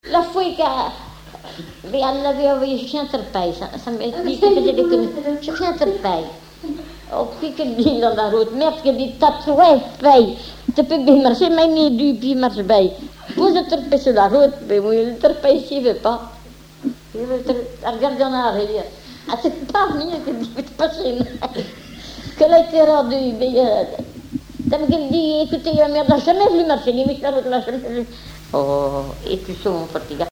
Langue Patois local
Genre conte